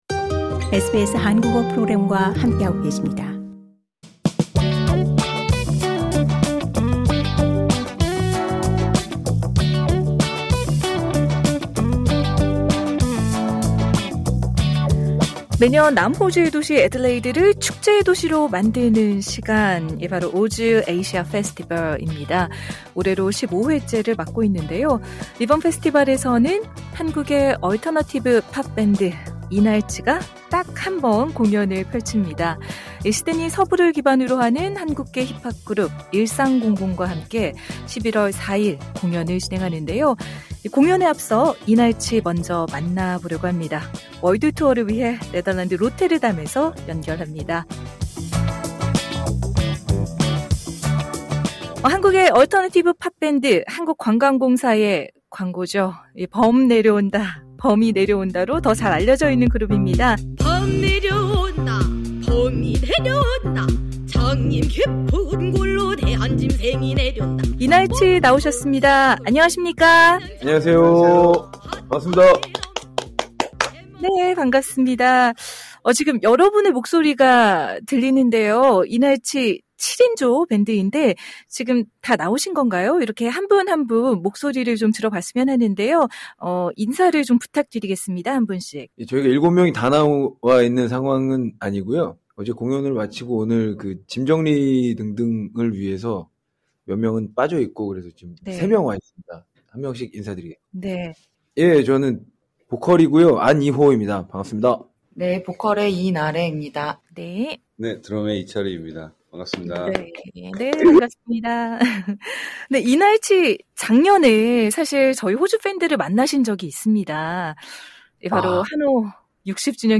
월드 투어를 위해 네덜란드 로테르담에서 연결합니다.
지금 여러 분의 목소리가 들리는데요.